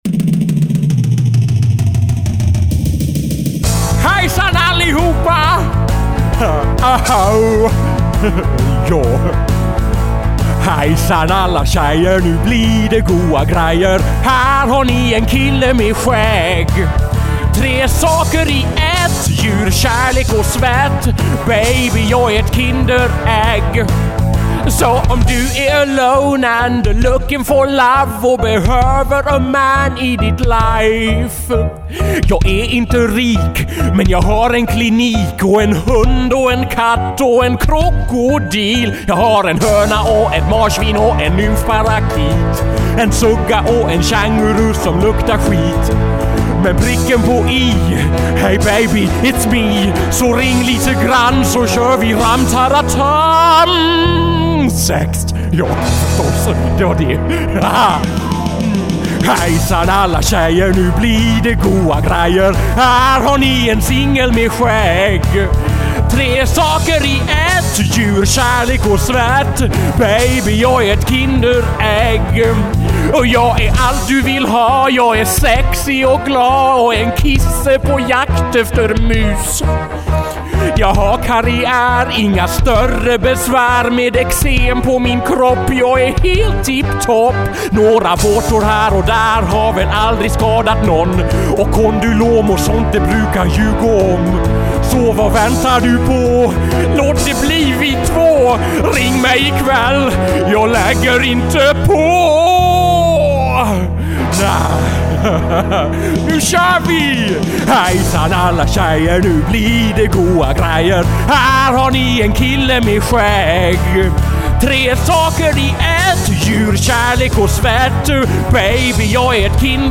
Dessutom i stereo!